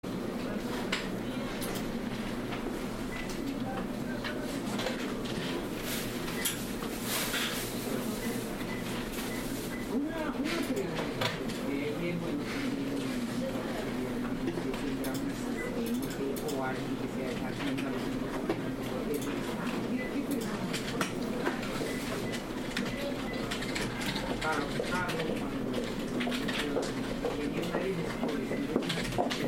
OfficeAmbience.mp3